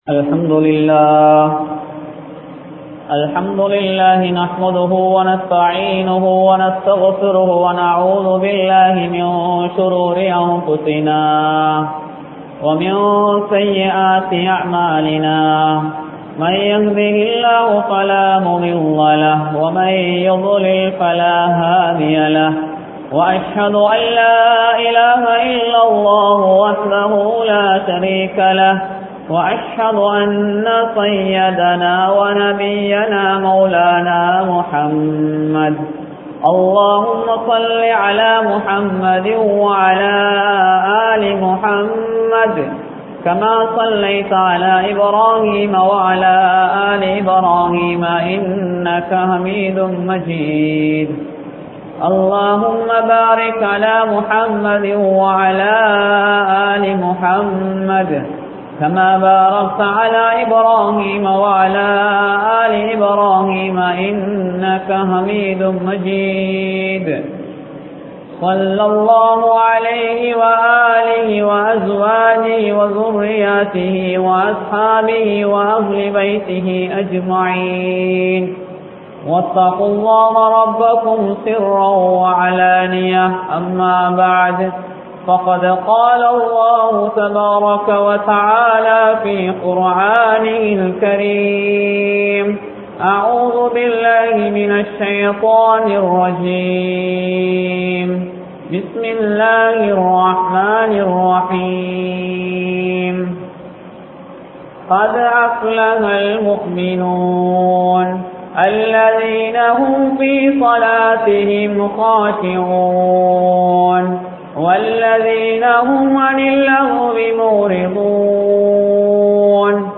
Pathavi Oru Amaanitham (பதவி ஒரு அமானிதம்) | Audio Bayans | All Ceylon Muslim Youth Community | Addalaichenai
Yathianthoatta, Town Jumua Masjidh